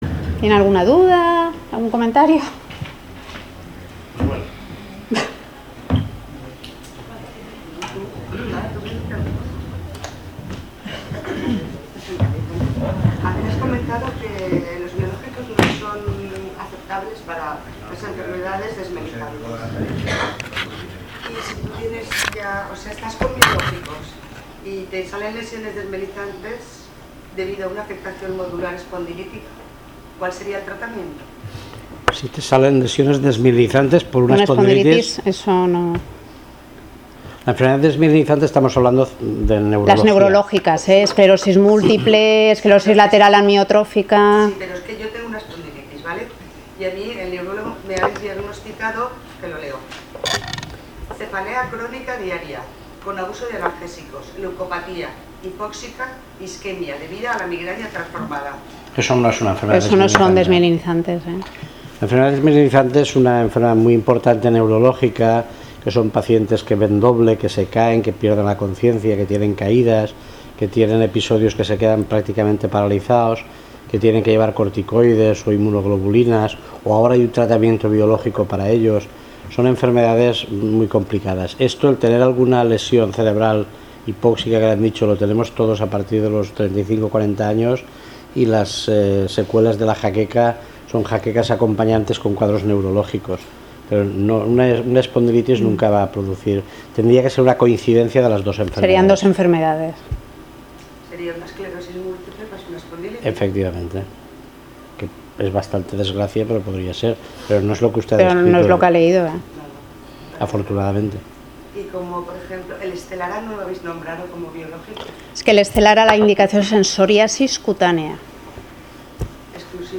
Salón de actos del Hospital General Universitario de Valencia. Conferencia sobre las Novedades en el tratamiento de las enfermedades inflamatorias: - Artritis Reumatoide - Espondiloartritis - Artropatía psoriásica
conferencia_biologicos_2.mp3